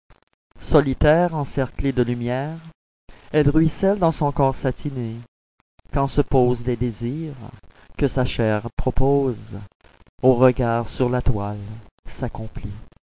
Récital de poésie en Mp3